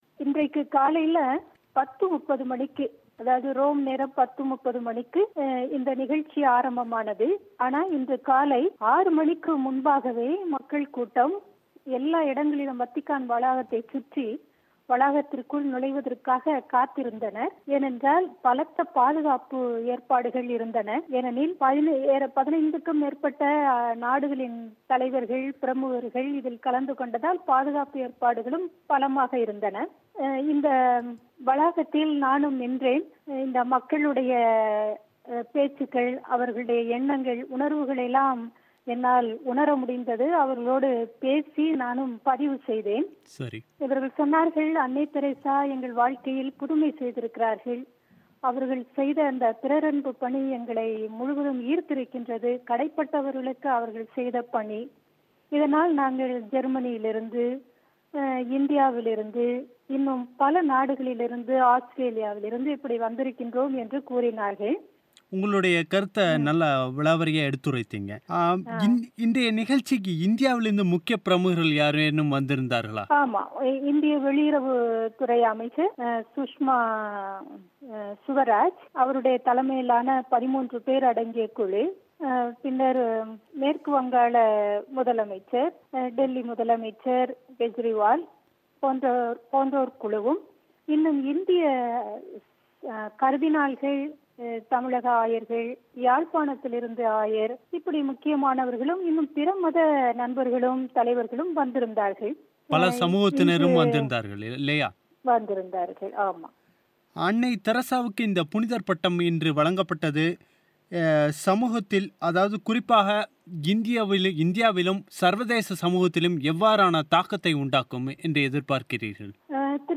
நேர்முகம்